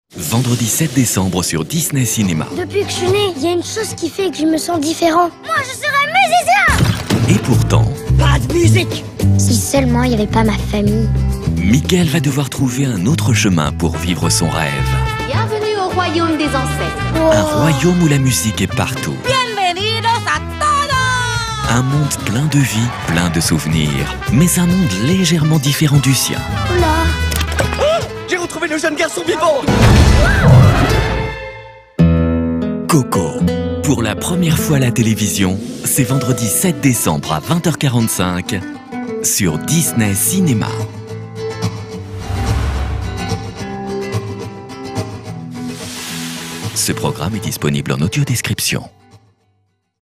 COCO / DISNEY chaleureux, bienveillant - Comédien voix off
Genre : voix off.